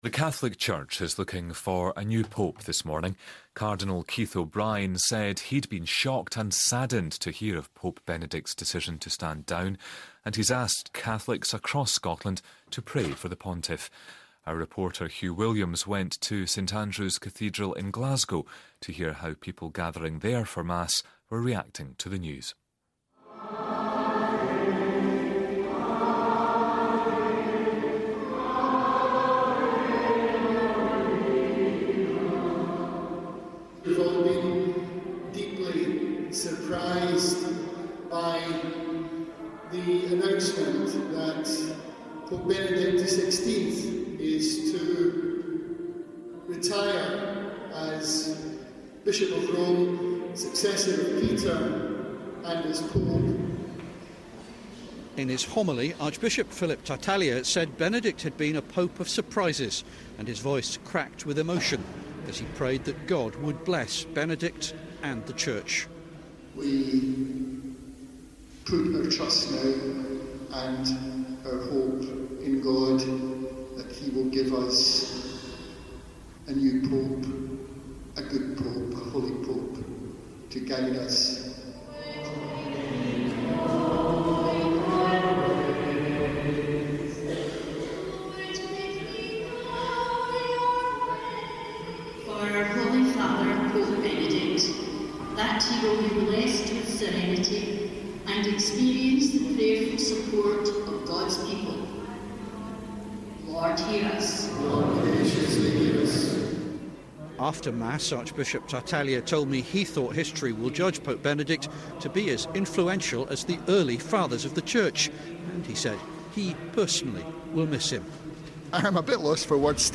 Reaction from the faithful at St Andrew's Cathedral in Glasgow, on the day Benedict XVI announced he was stepping down from the papacy.